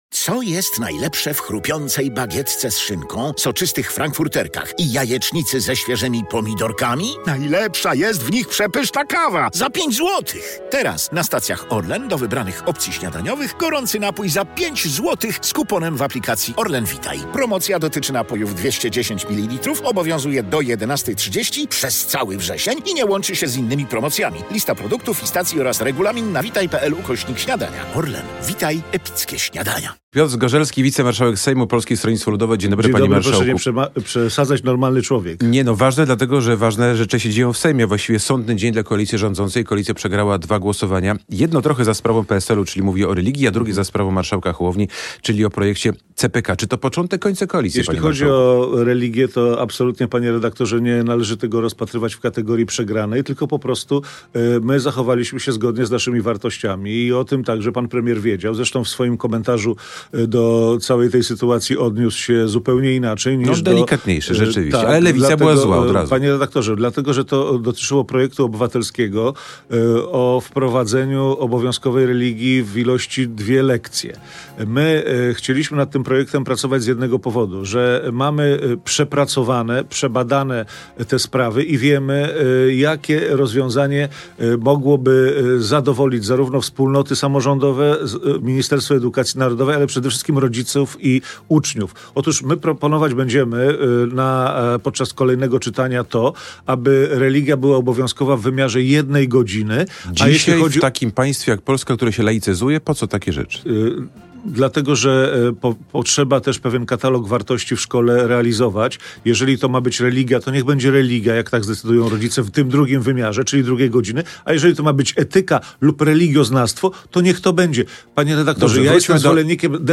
W sobotnie poranki, tuż po godz. 8:30 Krzysztof Ziemiec zaprasza na rozmowy nie tylko o polityce. Wśród gości dziennikarza osoby z pierwszych stron gazet.